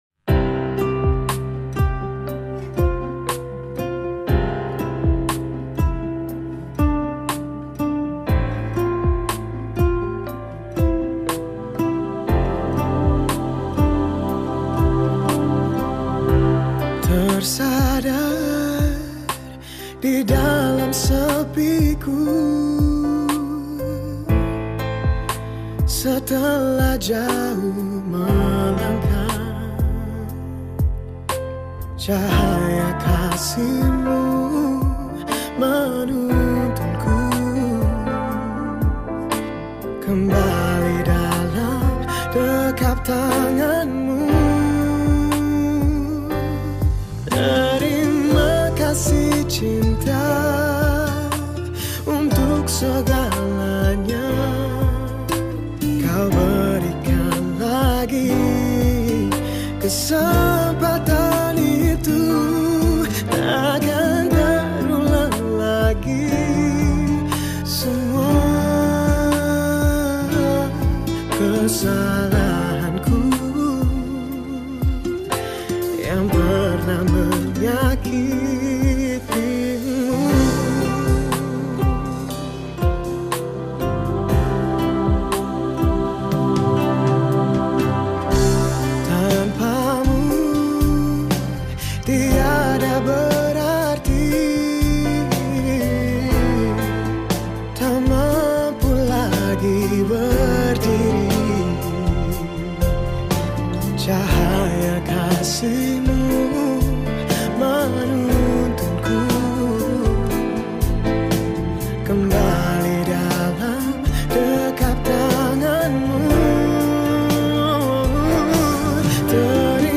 Indonesian Songs
Violin Tags